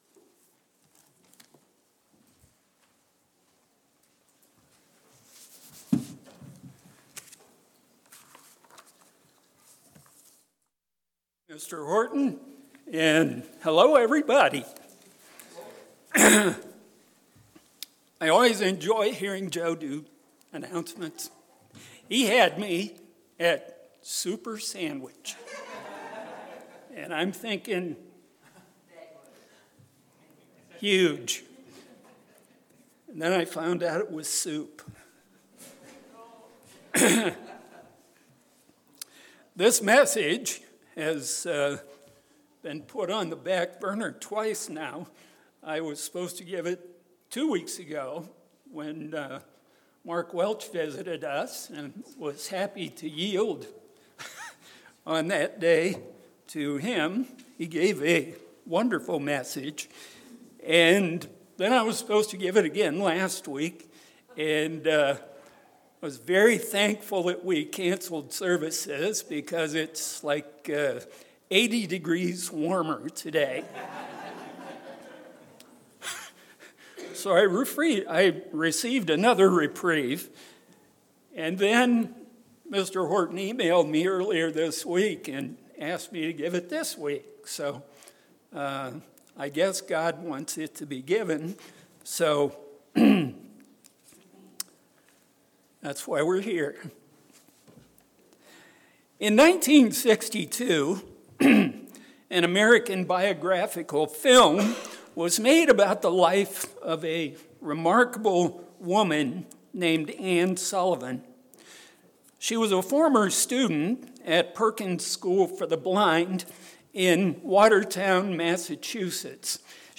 Given in Mansfield, OH